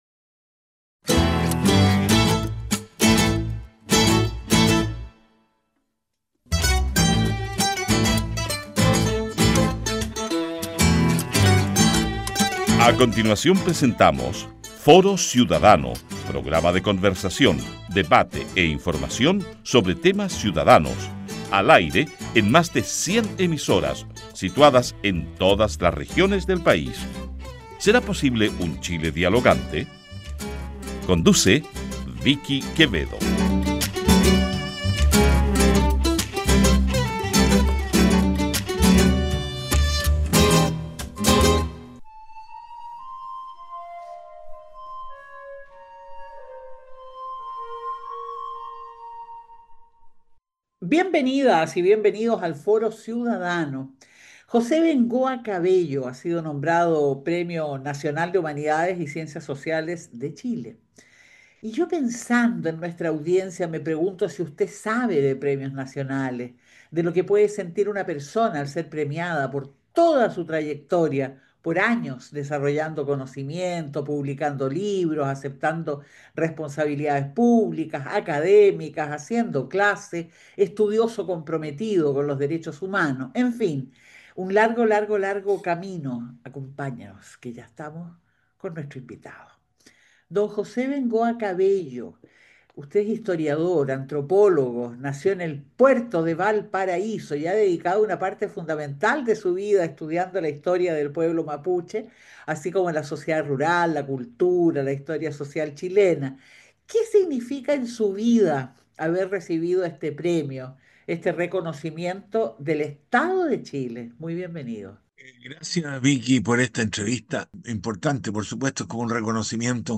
Conversamos con José Bengoa Cabello, Premio Nacional de Humanidades y Ciencias Sociales, Chile, 2025. Con los datos duros del resultado de las presidenciales, realiza un exhaustivo análisis por sectores rurales y urbanos del país.